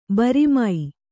♪ bari mai